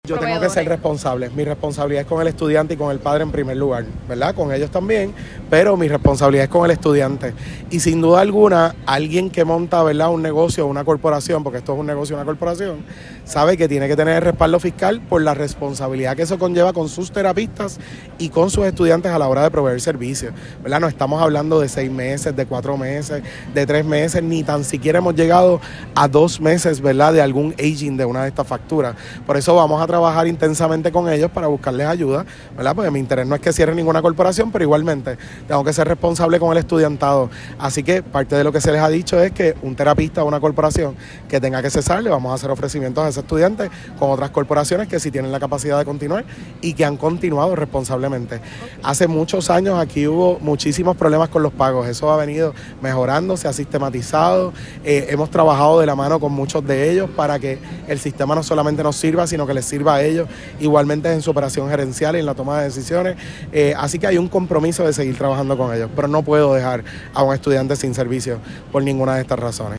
Las expresiones del secretario se dieron durante la congregación de bandas escolares de toda la isla, las cuales fueron desfilando por toda la avenida Carlos Chardón, en ruta hacia el DE en plena semana educativa.